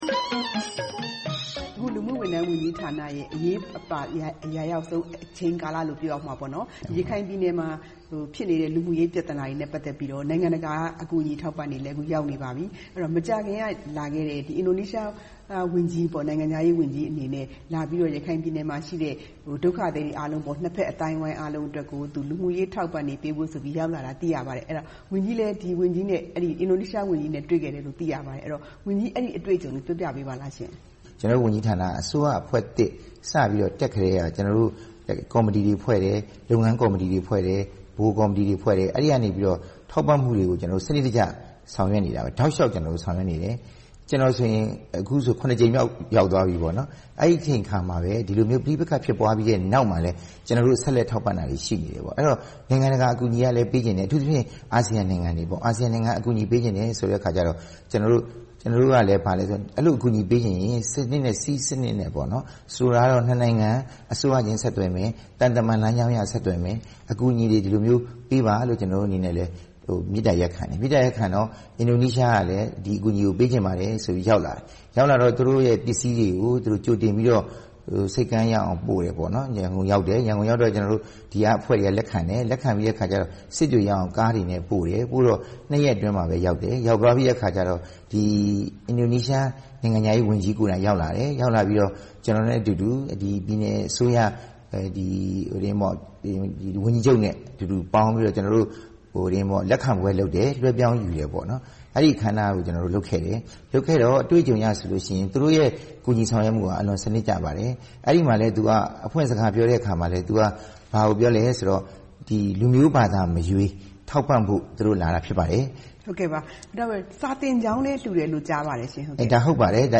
နေပြည်တော်မှာ သီးသန့်တွေ့ဆုံမေးမြန်းထားပါတယ်။